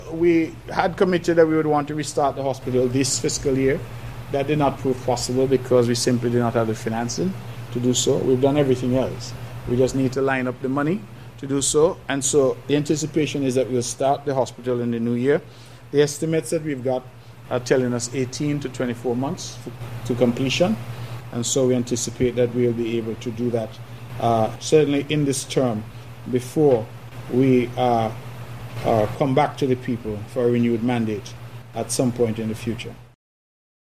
During a discussion with the media the Hon. Mark Brantley, shared that his administration hopes that work on the Alexandra Hospital will resume in the new fiscal year and that the main challenge is to secure funding.